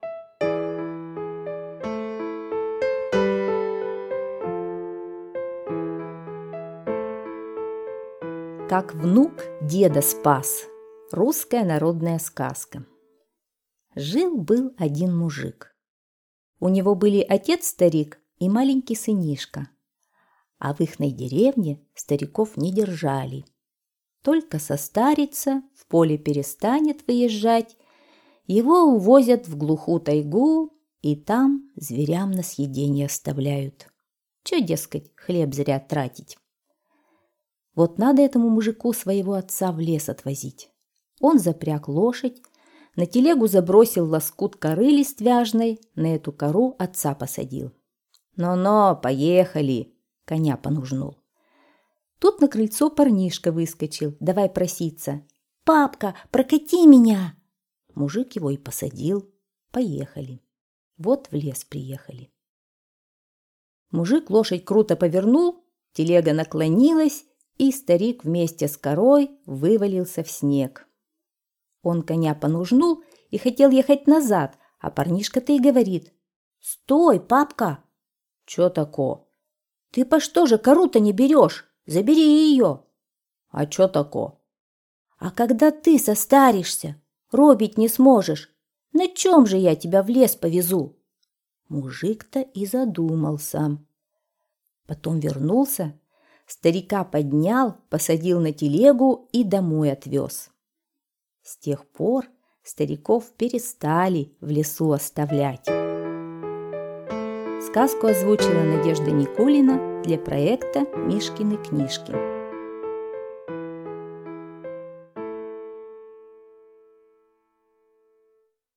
Как внук деда спас — русская народная аудиосказка. Сказка о том, как раньше немощных стариков в глухую тайгу увозили и оставляли умирать.